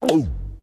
plantDie.ogg